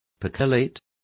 Complete with pronunciation of the translation of percolate.